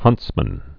(hŭntsmən)